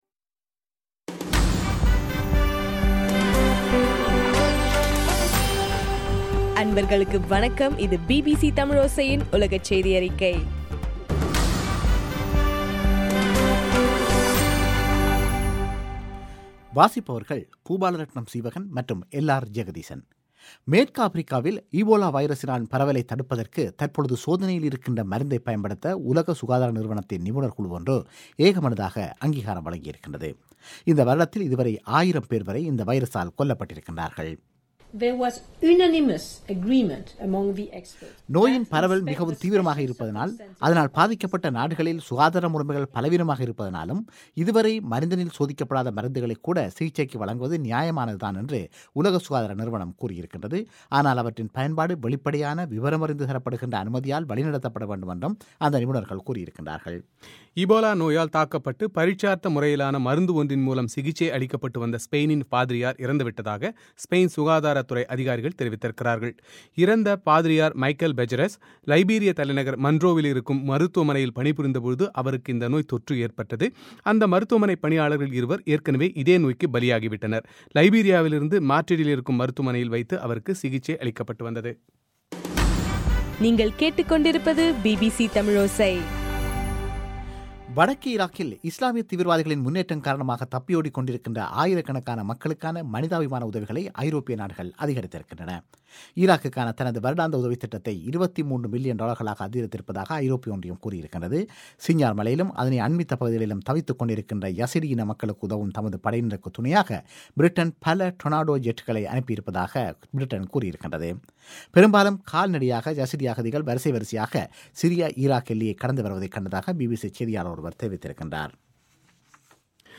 ஆகஸ்ட் 12 பிபிசியின் உலகச் செய்திகள்